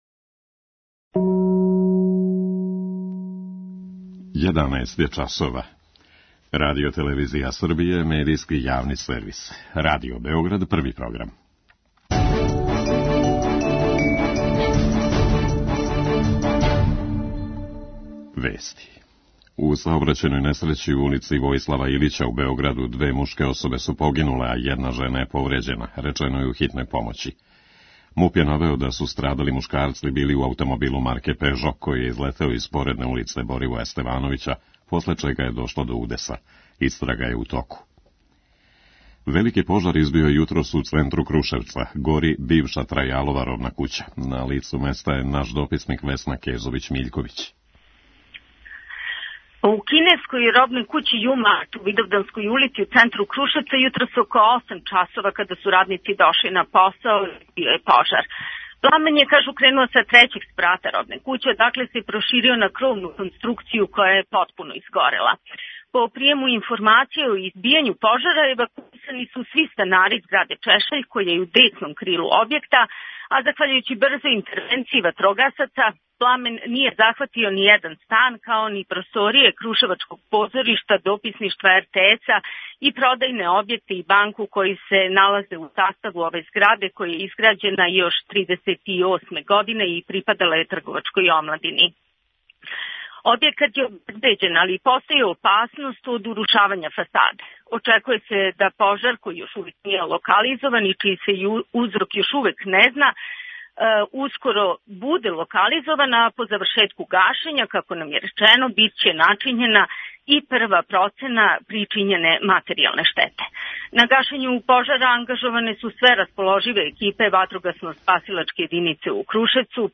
на никлхарфи.